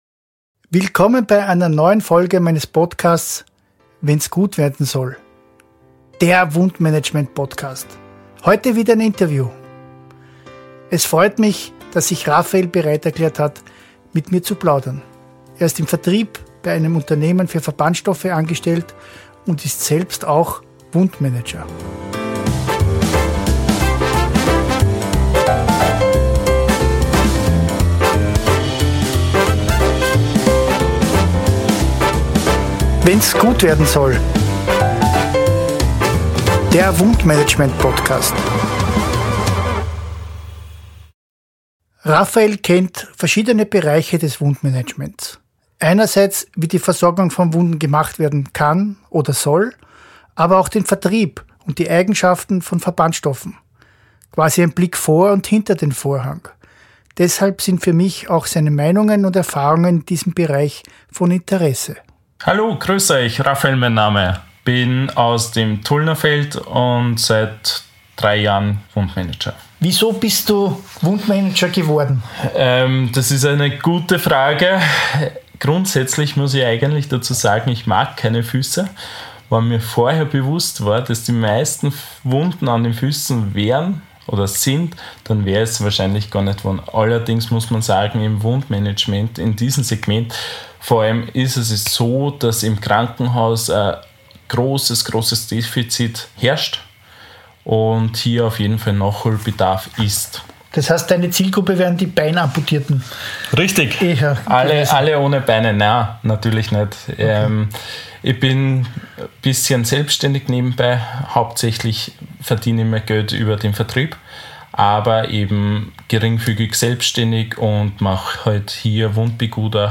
Ein Fachinterview